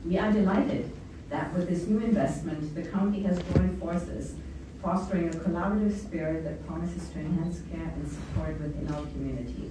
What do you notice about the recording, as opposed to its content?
A grand opening ceremony for the Renfrew County Mesa HART Hub was held on Thursday, February 5th